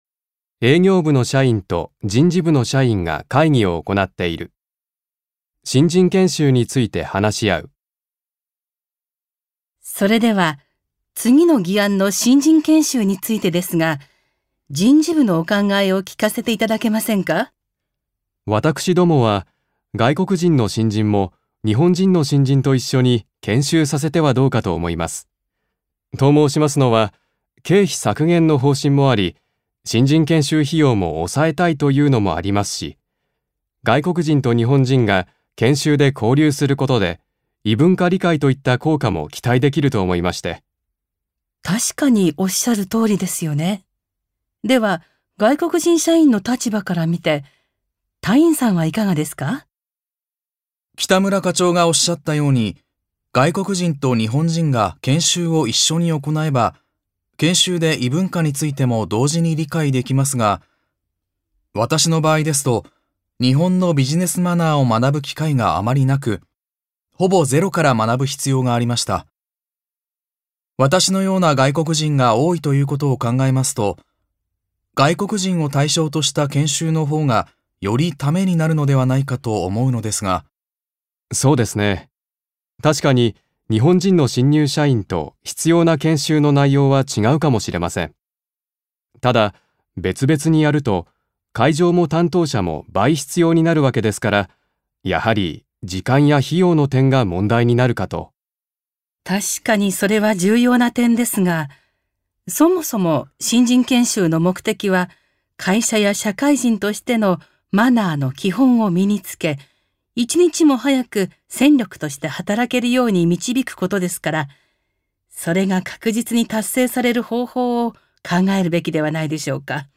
1. 会話（意見を述べる・ほかの人の意見に賛成／反対する）
場面：営業部えいぎょうぶの社員と人事部じんじぶの社員が会議を行っている。新人研修けんしゅうについて話し合う。